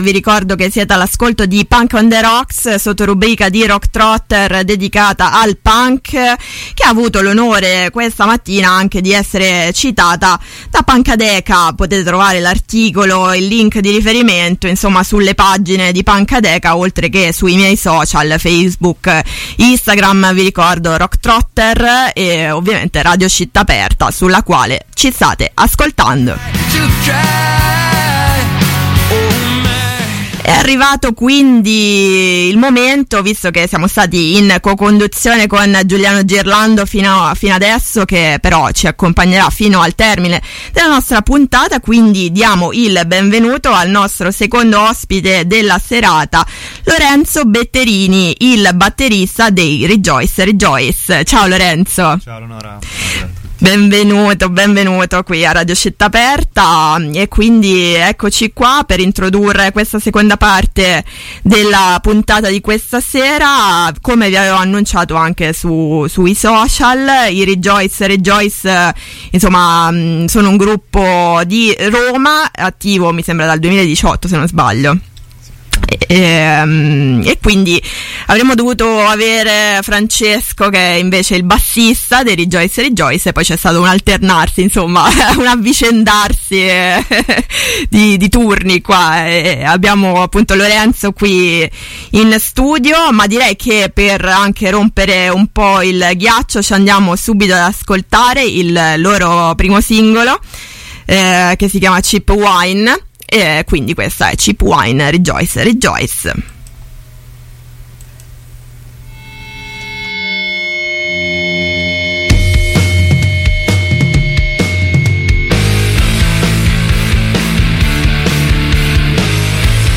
Intervista ai Rejoyce/Rejoice | Punk on the Rocks | 28-2-22 | Radio Città Aperta